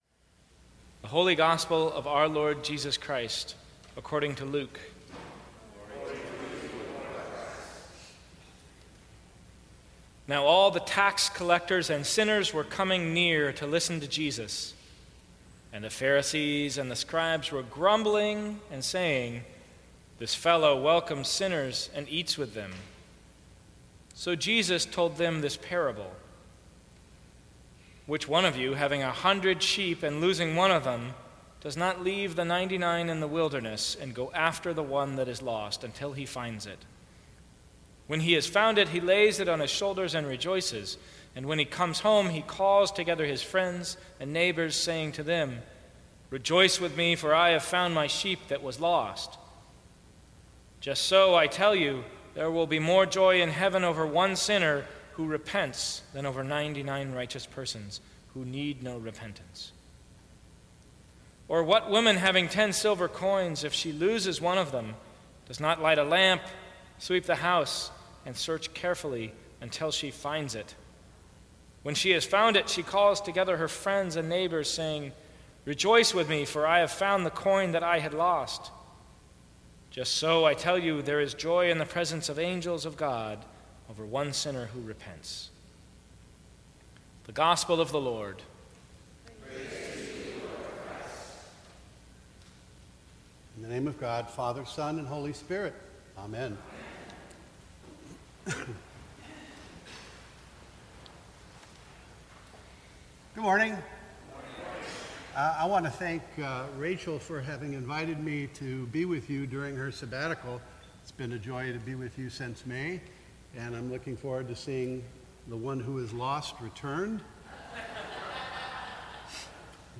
Sermons from St. Cross Episcopal Church Attracted to the fragrance of God Sep 11 2016 | 00:14:16 1x 00:00 / 00:14:16 Subscribe Share Apple Podcasts Spotify Overcast RSS Feed Share Link Embed